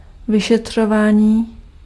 Ääntäminen
IPA : /ɪnˌvɛstəˈɡeɪʃən/